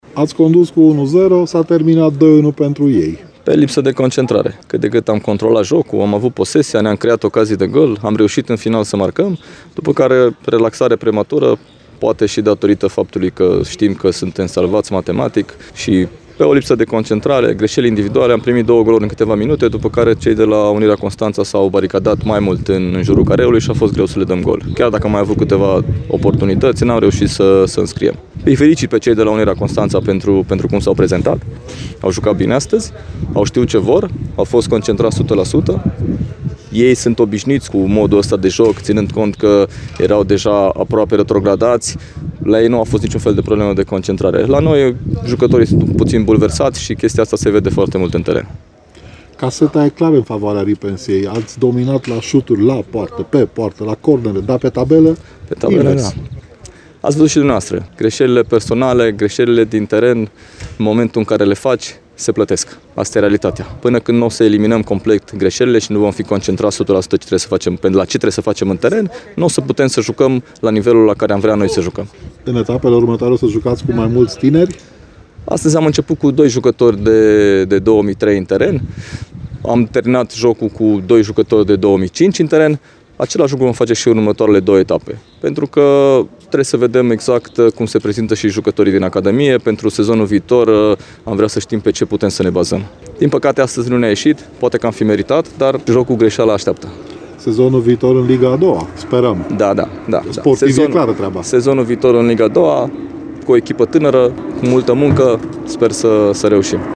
La final de meci